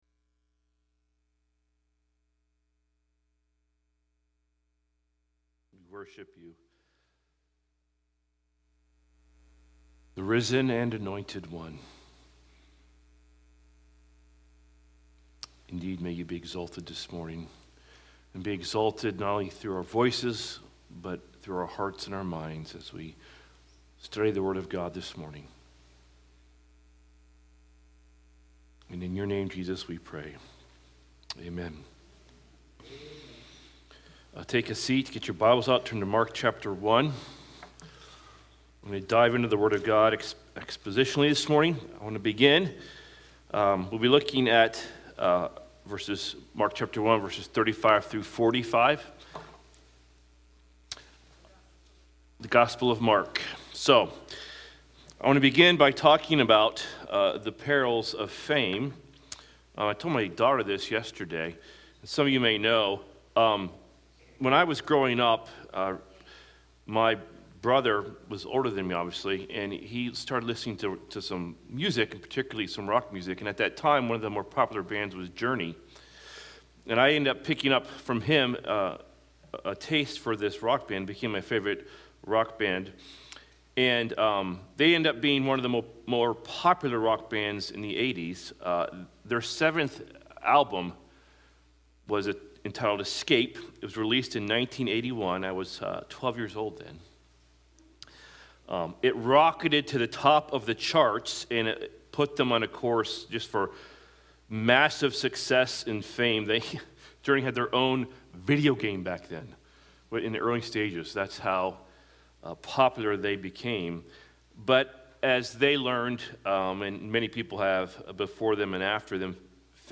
Sermons from Bible Chapel of Auburn, WA